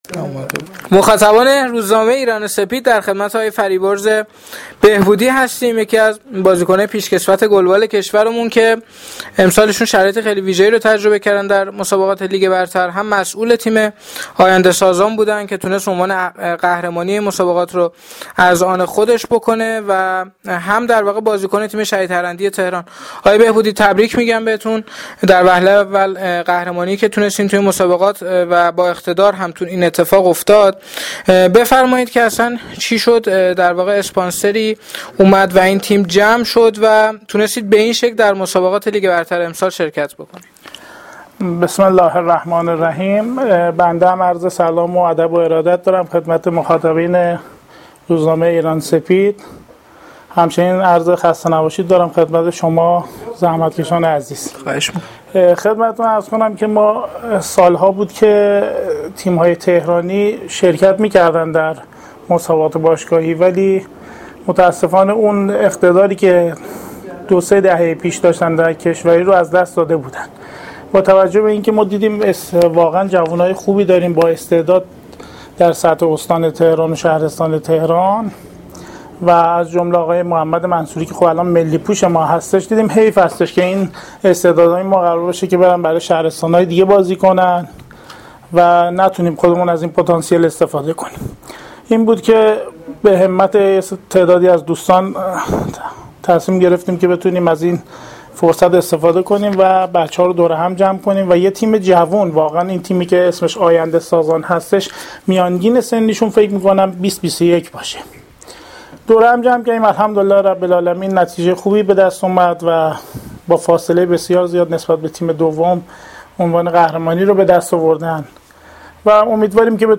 فایل صوتی مصاحبه با بازیکنان و مربیان حاضر در دوازدهمین دوره لیگ برتر و دسته اول گلبال آقایان
در بخش اول، بازیکنان تیم آینده سازان تهران پس از کسب عنوان قهرمانی در مسابقات لیگ برتر گلبال، به ارائه نقطه نظرات خود در خصوص این مسابقات پرداختند.